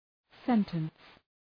Προφορά
{‘sentəns}